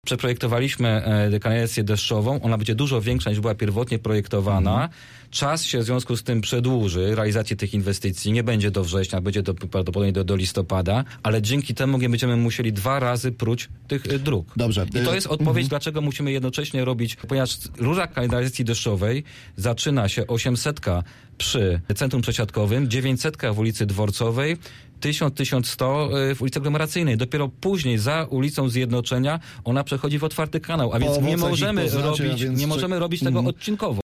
Większość inwestycji robimy za środki unijne, np. Trasa Aglomeracyjna miała być zrealizowana wówczas, kiedy je pozyskamy – mówił wiceprezydent Zielonej Góry w Rozmowie Punkt 9: